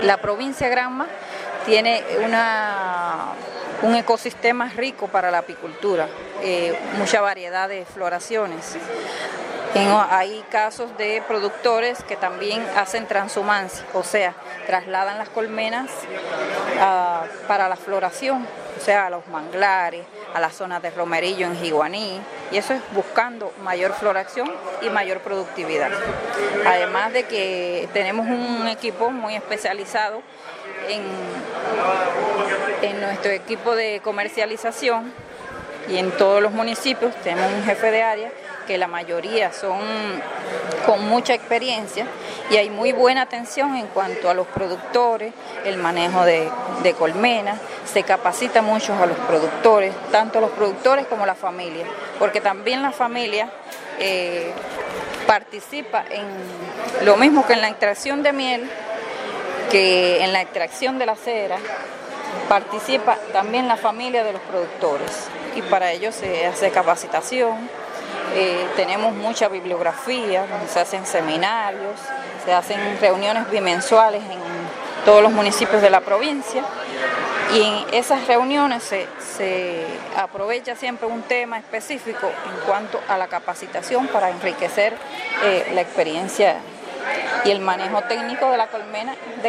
colmena2.mp3